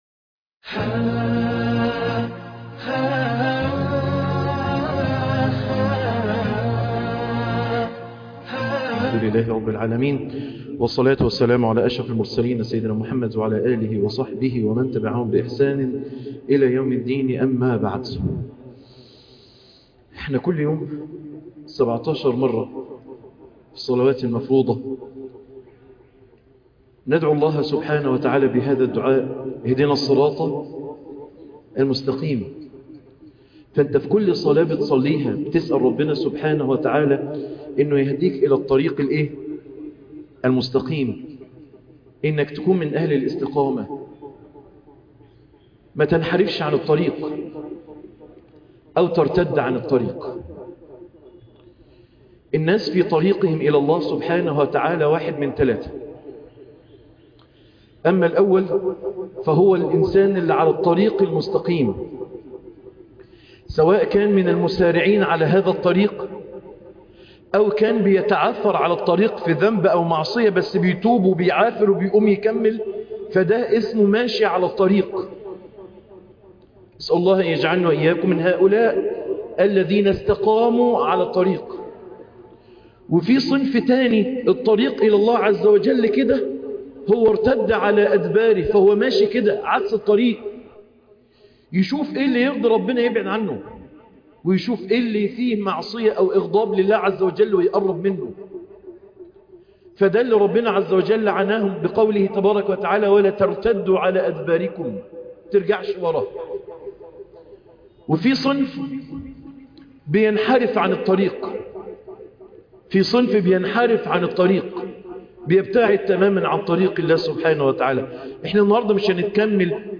إطعام الطعام عبودية الصالحين درس التروايح